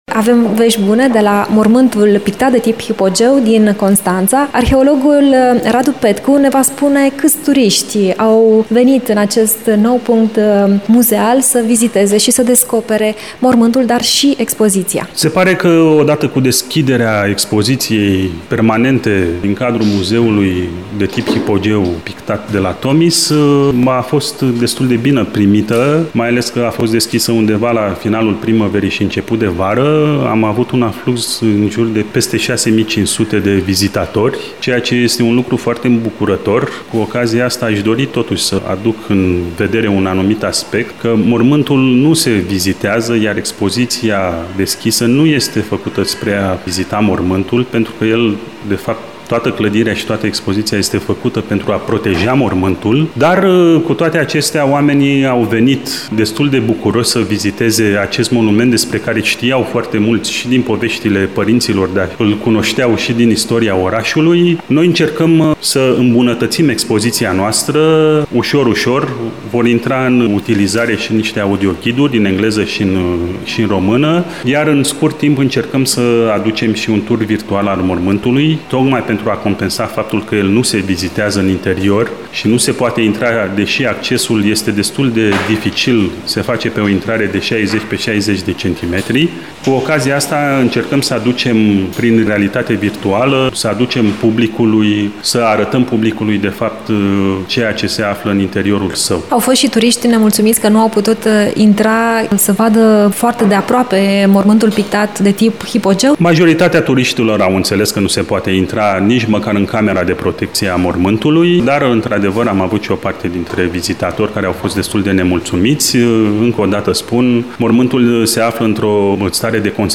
Ne vorbește despre toate acestea arheologul